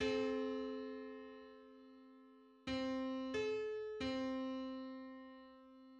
Just: 105:64 = 857.10 cents.
Public domain Public domain false false This media depicts a musical interval outside of a specific musical context.
Hundred-fifth_harmonic_on_C.mid.mp3